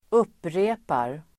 Uttal: [²'up:re:par]
upprepar.mp3